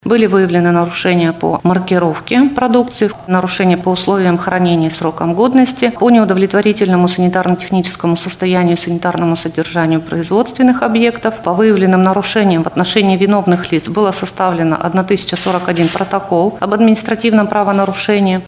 на пресс-конференции ТАСС-Урал